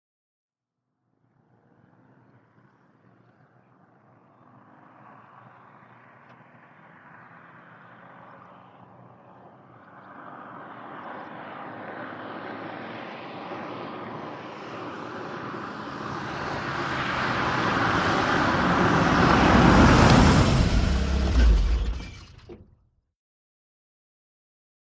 VEHICLES AUSTIN MONTEGO: EXT: Austin montego, arrive, switch off briskly.